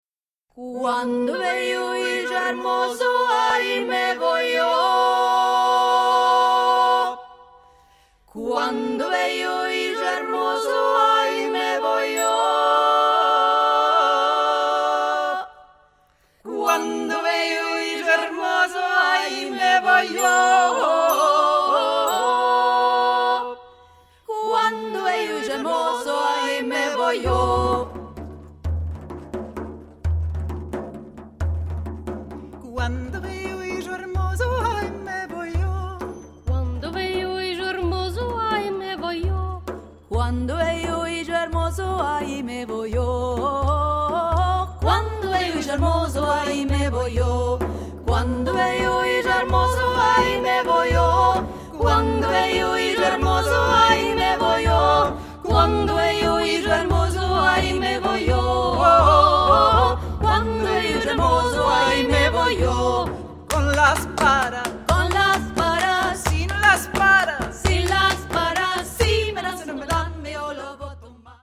Las tres ermanikas: our Sephardic programme: